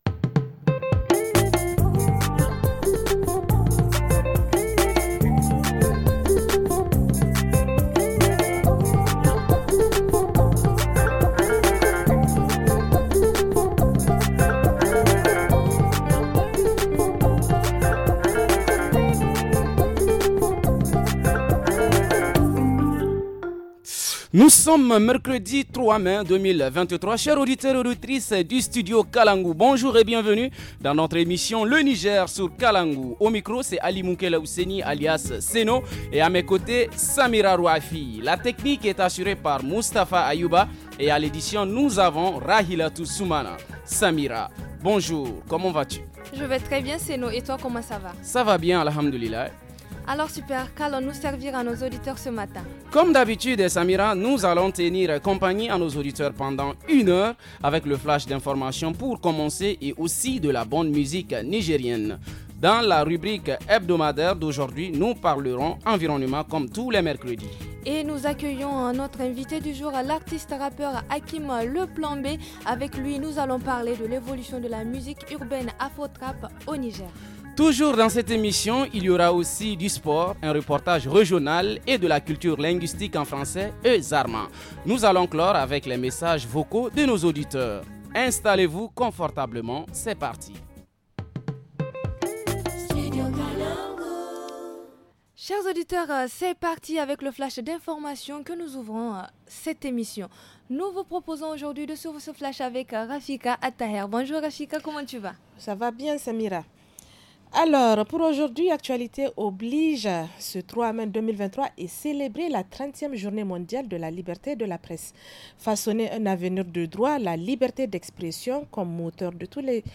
Le reportage à Agadez sur l’importance du petit déjeuner chez les enfants.